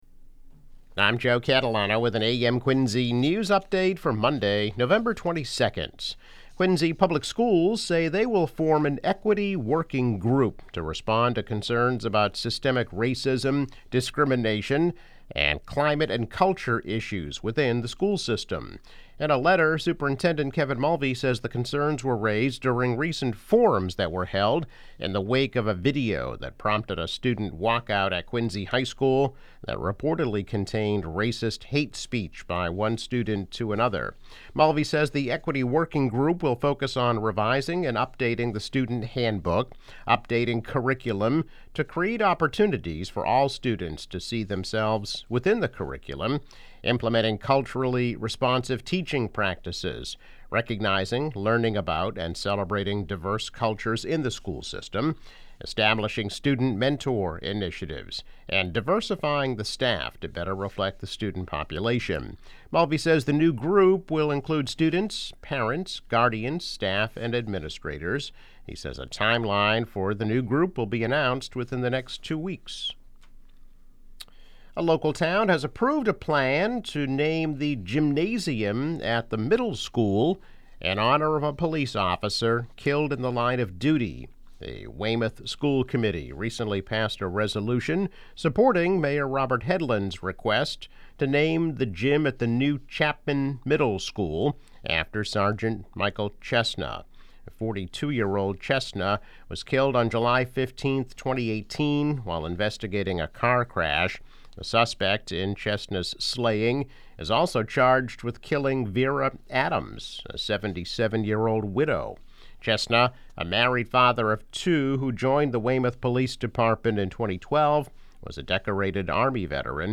School Equity. Officer Honored. Holiday Travel.  Daily news, weather and sports update.